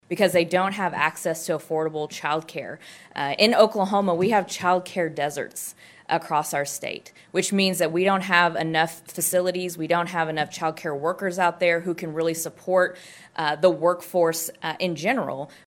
CLICK HERE to listen to commentary from House Minority Leader Cyndi Munson.